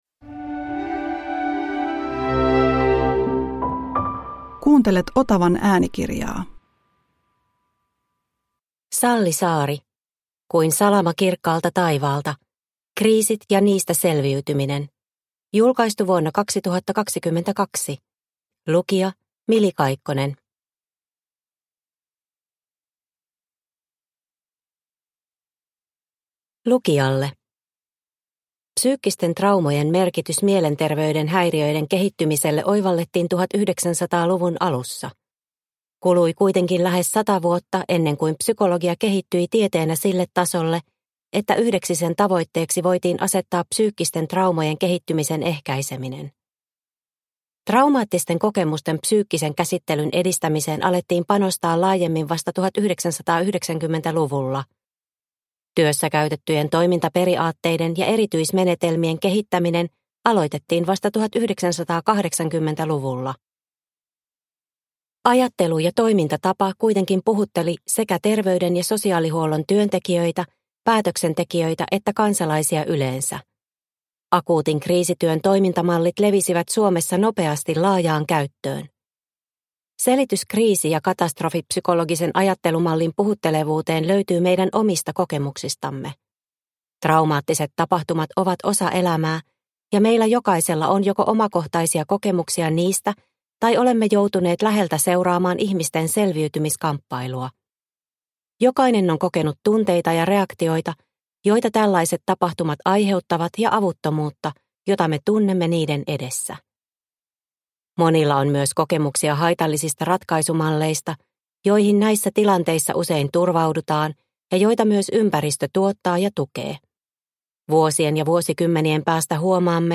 Kuin salama kirkkaalta taivaalta – Ljudbok – Laddas ner